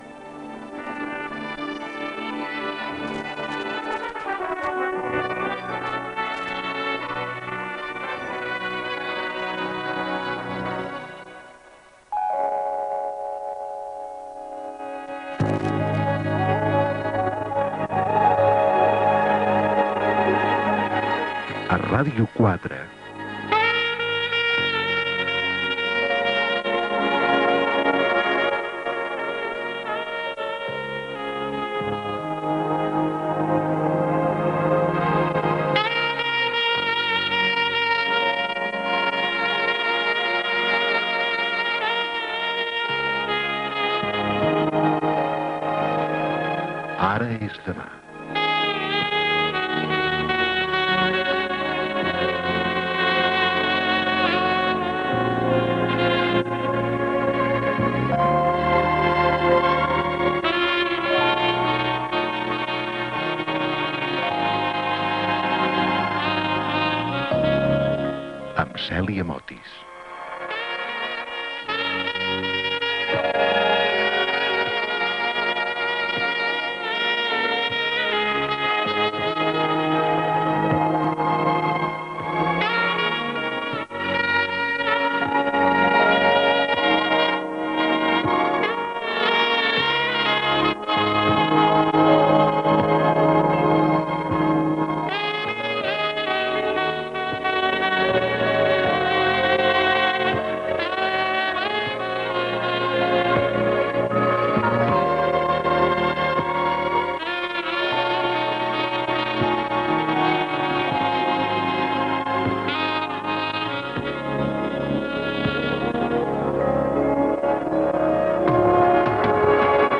Sintonia de Ràdio 4, careta del programa, presentació, comentari sobre el canvi d'aspecte a la nit del transformista
Gènere radiofònic Entreteniment